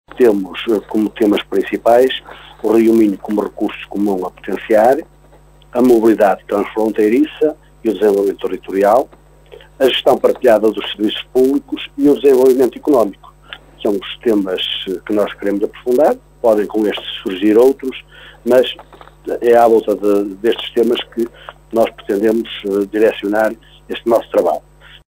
O autarca de Vila Nova de Cerveira , Fernando Nogueira, já adiantou à Rádio caminha os temas que vão estar em discussão.